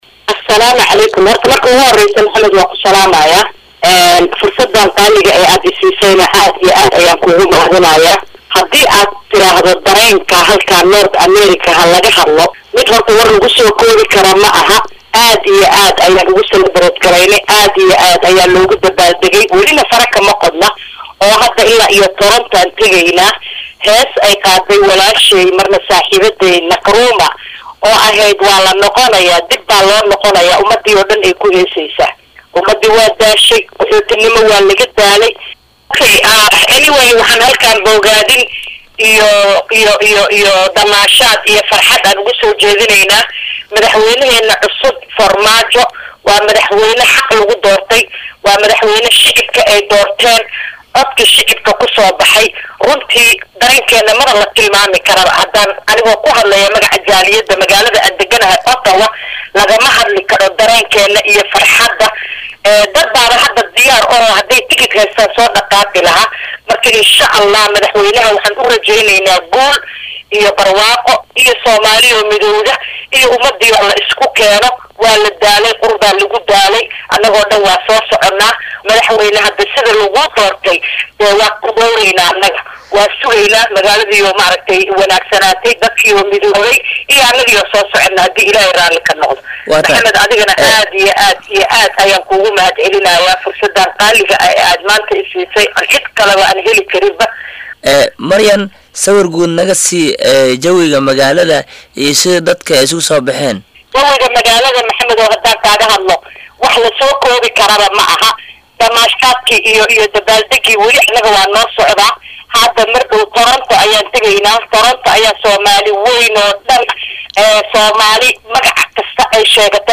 Halkan ka Dhageyso Wareysiga oo dhameystiran http